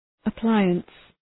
Προφορά
{ə’plaıəns}